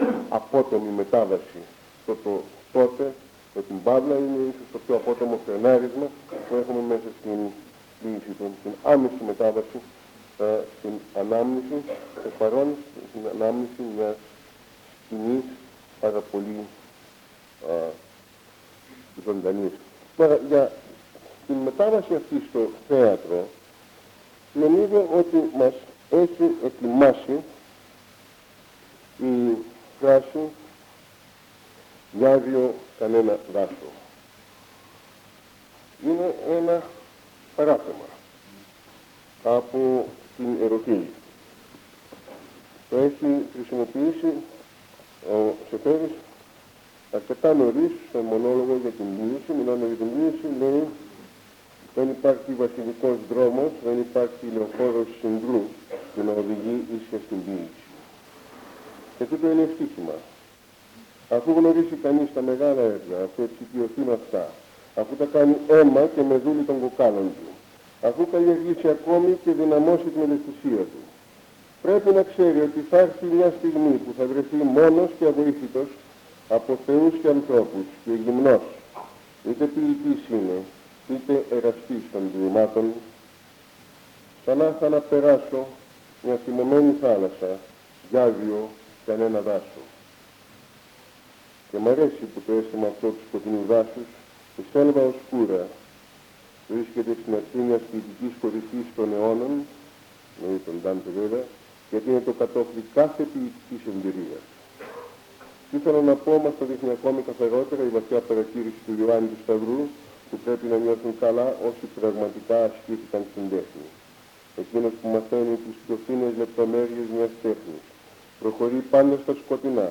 Εξειδίκευση τύπου : Εκδήλωση
Περιγραφή: Κύκλος Μαθημάτων